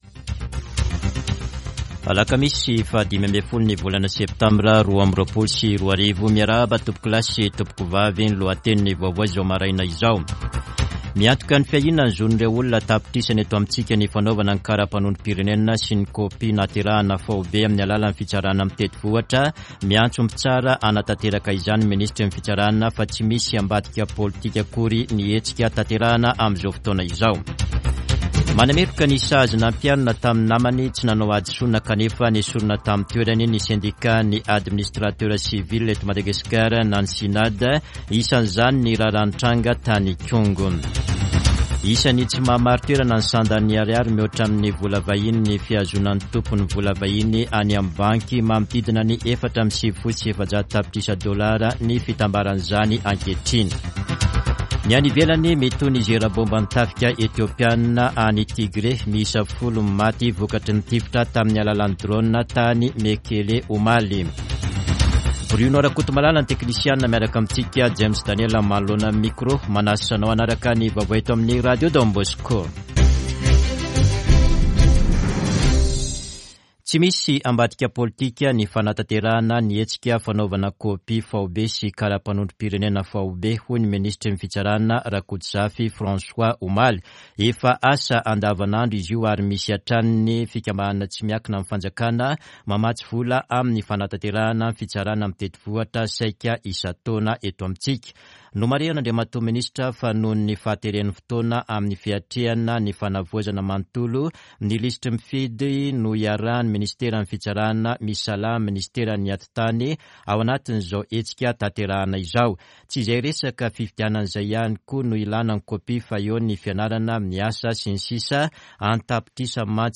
[Vaovao maraina] Alakamisy 15 septambra 2022